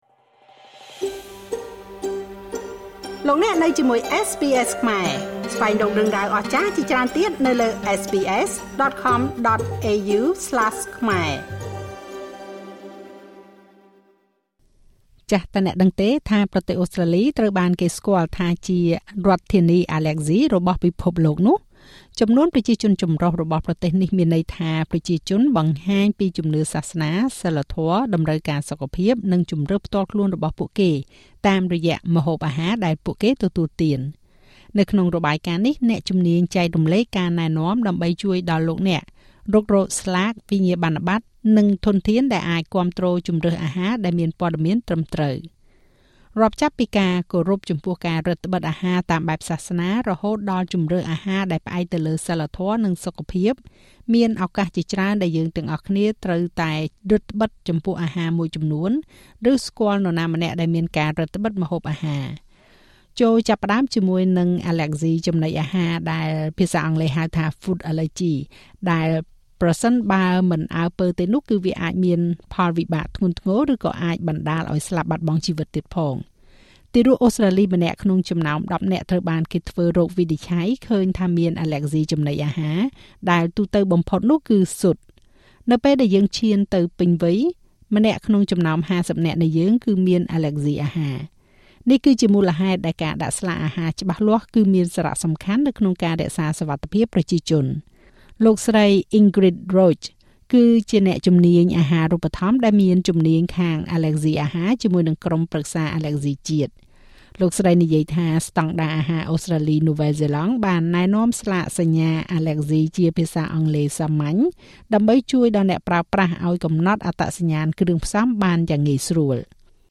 តើអ្នកដឹងទេថាប្រទេសអូស្ត្រាលីត្រូវបានគេស្គាល់ថាជារដ្ឋធានីអាឡែស៊ីរបស់ពិភពលោក? ប្រជាជនចម្រុះជាតិសាសន៍របស់យើងមានន័យថា ពួកយើងបង្ហាញពីជំនឿសាសនា សីលធម៌ តម្រូវការសុខភាព និងជម្រើសផ្ទាល់ខ្លួនរបស់យើងតាមរយៈអាហារដែលយើងទទួលទាន។ នៅក្នុងរបាយការណ៍នេះ អ្នកជំនាញចែករំលែកការណែនាំដើម្បីជួយដល់លោកអ្នក រុករកស្លាក វិញ្ញាបនបត្រ និងធនធានដែលអាចប្រាប់យើងនូវព័ត៌មានអំពីជម្រើសអាហារដែល ត្រឹមត្រូវ។